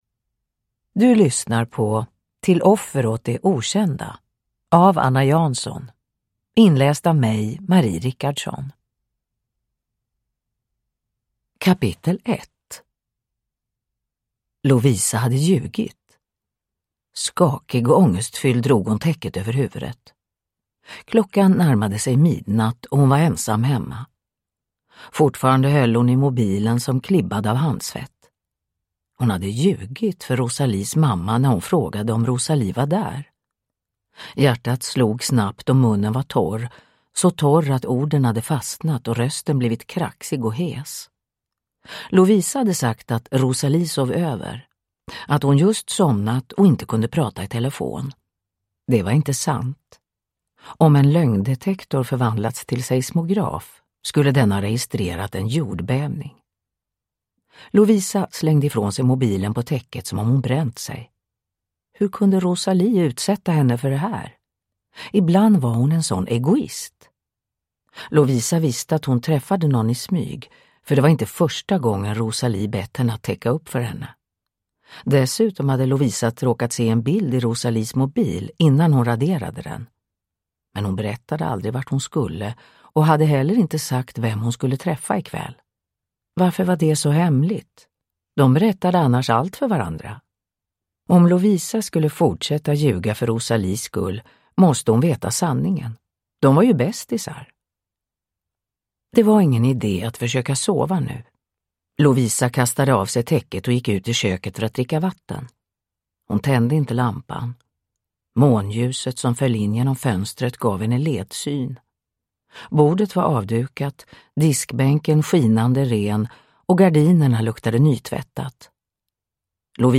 Till offer åt det okända (ljudbok) av Anna Jansson